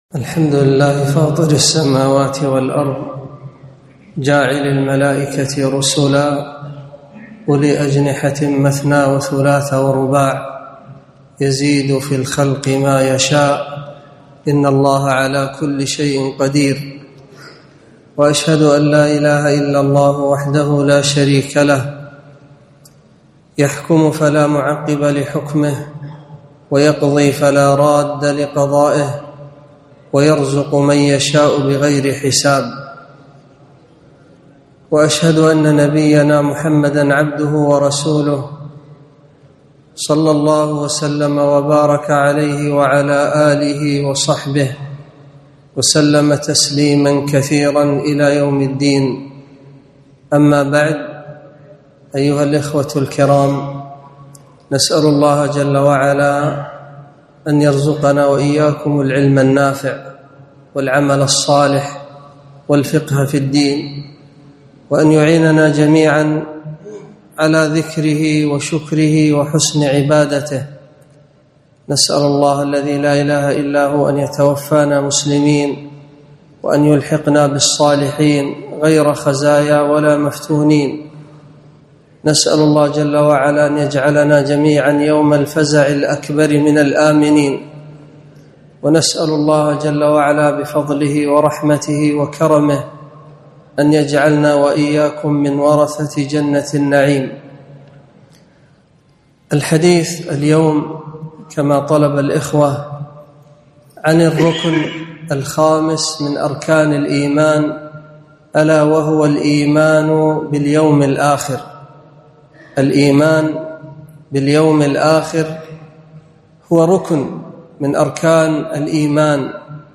محاضرة - الإيمان باليوم الآخر